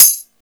Closed Hats
Hat (46).wav